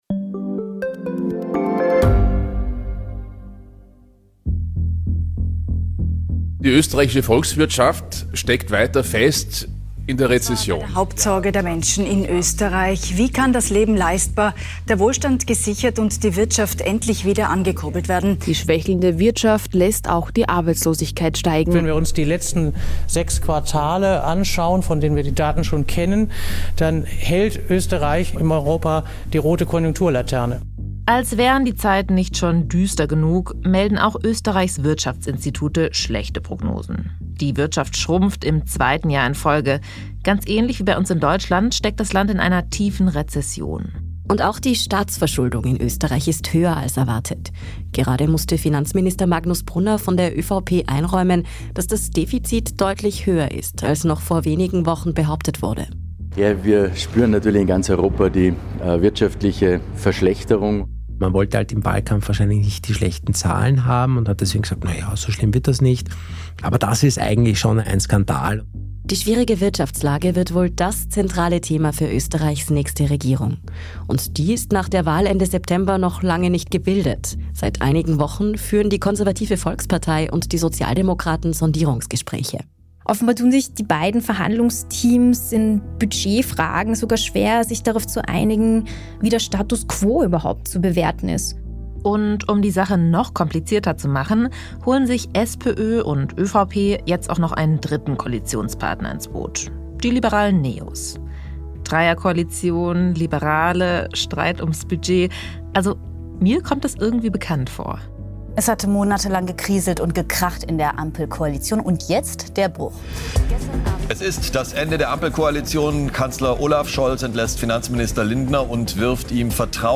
Bei einem Live-Auftritt haben wir gefragt, was passiert, wenn FPÖ-Chef Herbert Kickl Österreichs nächster Kanzler werden sollte.